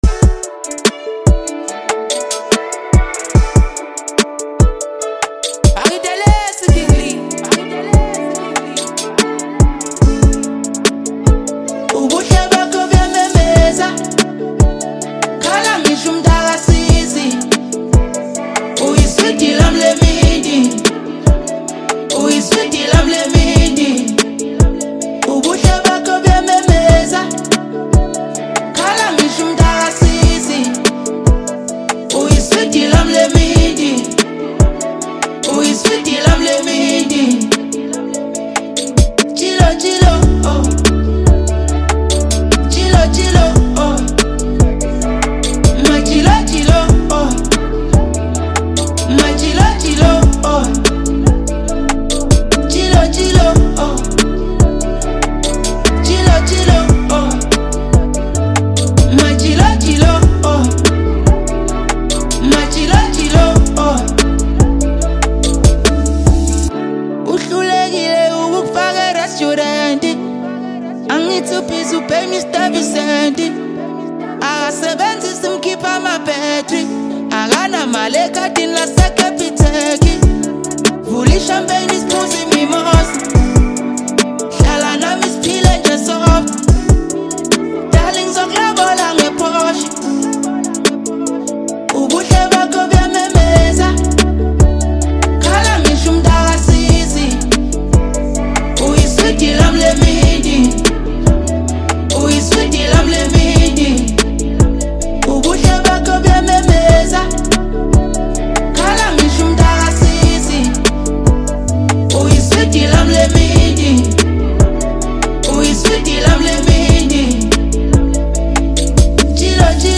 Home » Hip Hop » Amapiano » DJ Mix